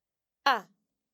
Listen once again to the difference between these two sounds, starting with the short a sound: